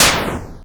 Shoot22.wav